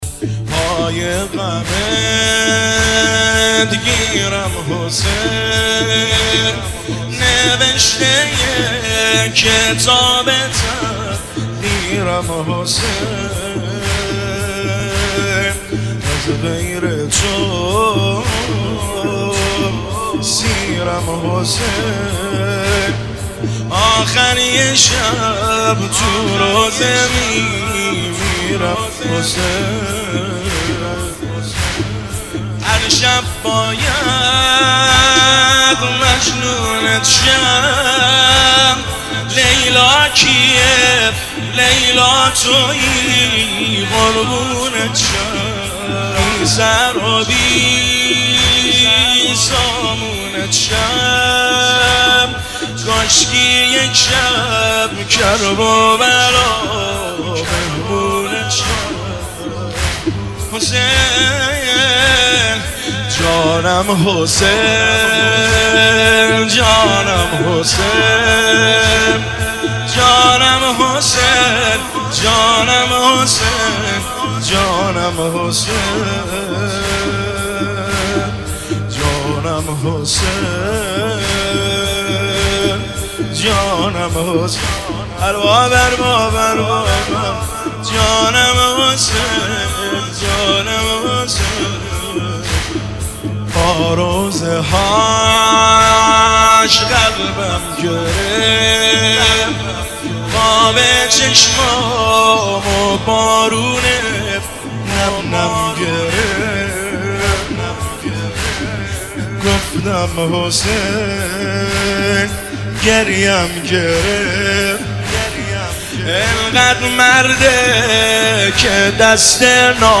زمینه – شب دوم شهادت امام هادی (ع) 1400